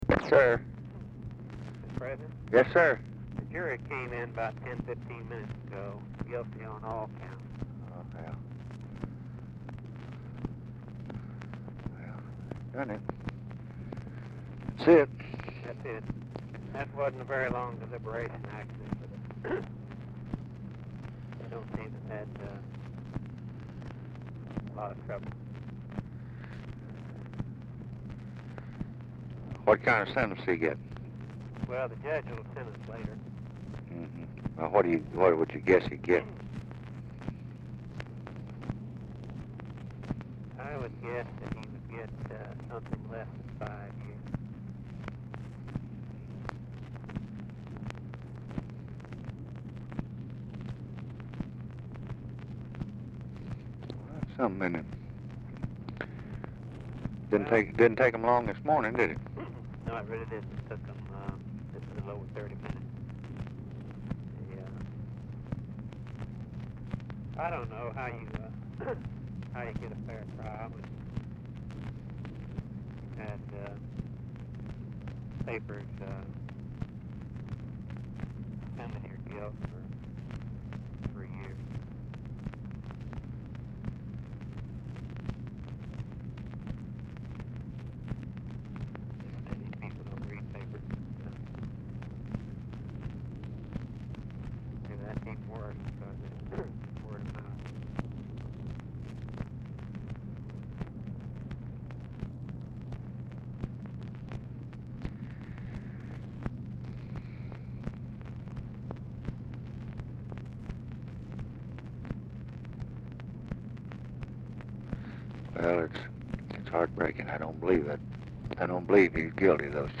Telephone conversation # 11414, sound recording, LBJ and RAMSEY CLARK, 1/29/1967, 10:45AM
CLARK IS DIFFICULT TO HEAR
Format Dictation belt
Location Of Speaker 1 Mansion, White House, Washington, DC